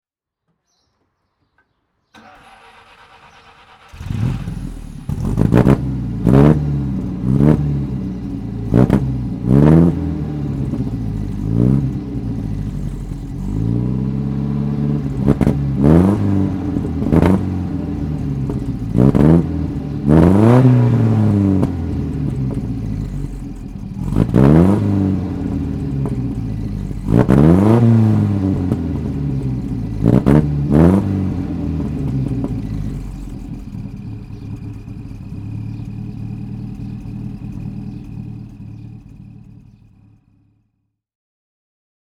Siata Daina 1400 Berlinetta (1950) - Starten und Leerlauf
Siata_Daina_1950.mp3